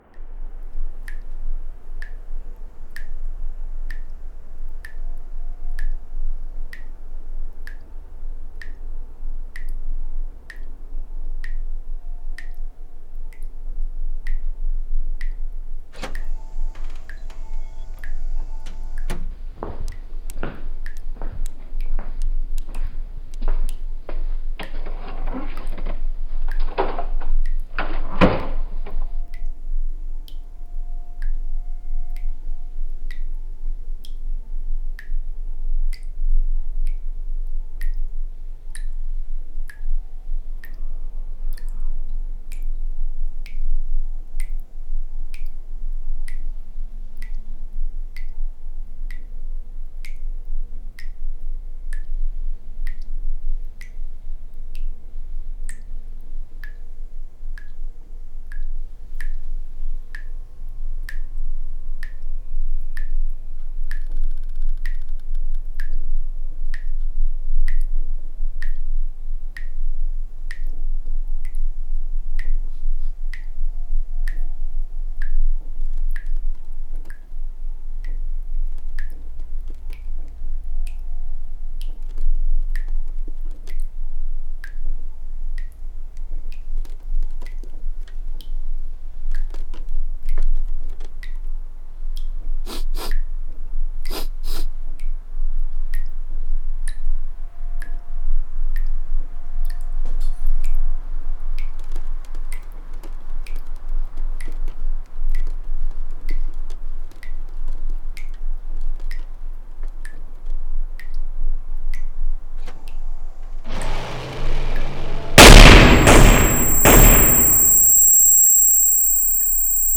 *PLEASE LISTEN WITH HEADPHONES*A sonic piece that creates an immersive 3D audio environment via the Haas effect
sniff.mp3